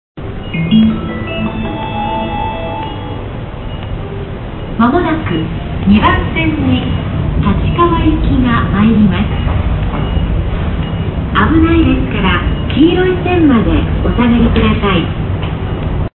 接近放送立川行き立川行きの接近放送です。